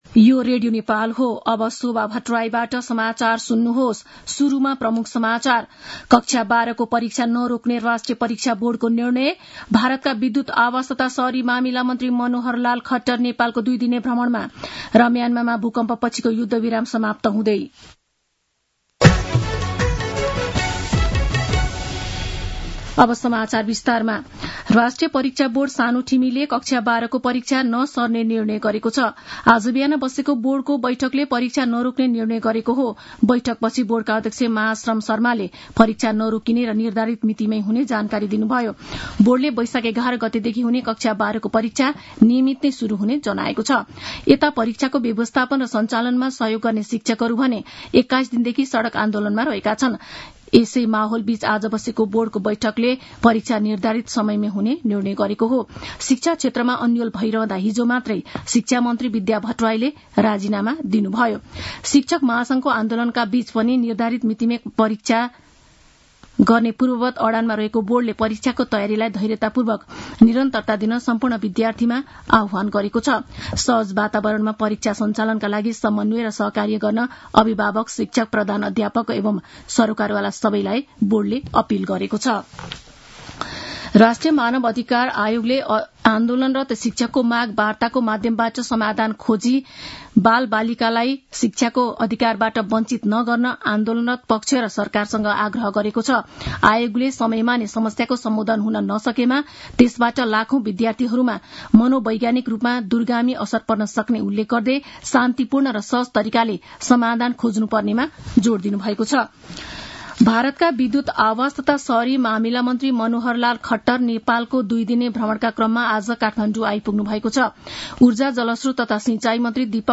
दिउँसो ३ बजेको नेपाली समाचार : ९ वैशाख , २०८२
3-pm-news-1-7.mp3